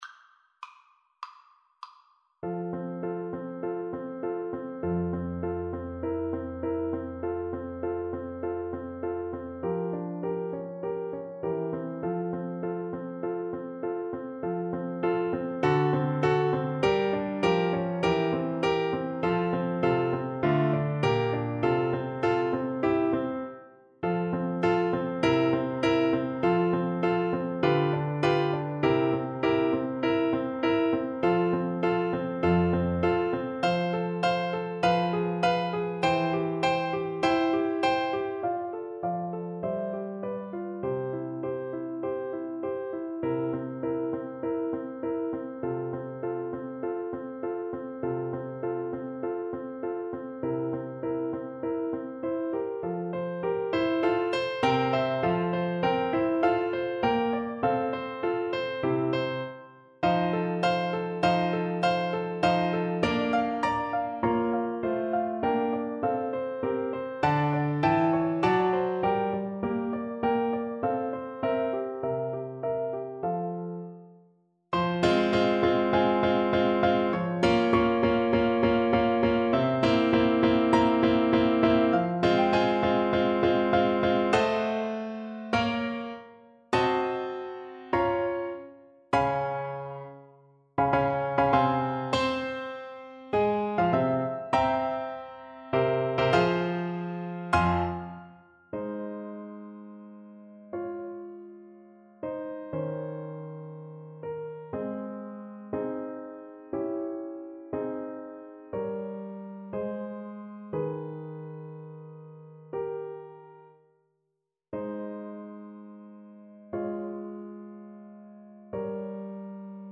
4/4 (View more 4/4 Music)
Classical (View more Classical Bassoon Music)